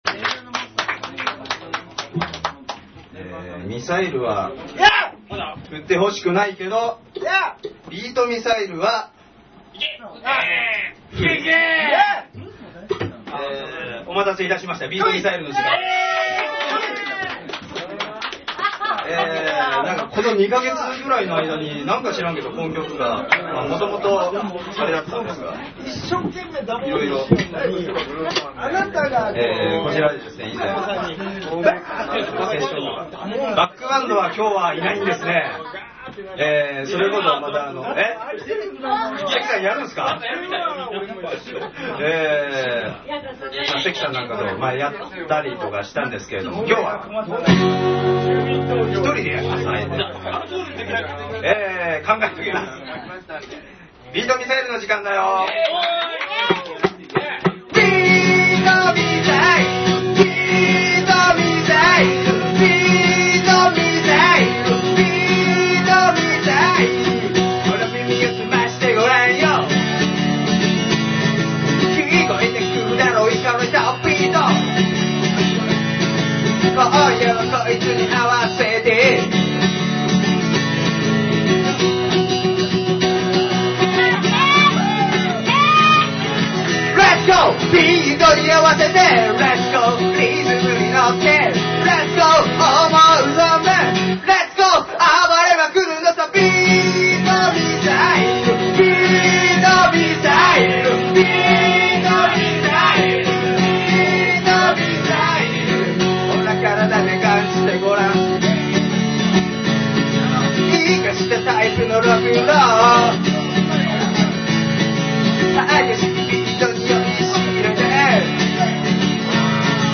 弾き語りになっても最初はやらなかったが、試しにやってみると大好評。
歌詞忘れたり、コード進行間違えたり、そんな状況ではあるが、
とにかく盛り上がったLIVEなのでこの音源をUPしてます。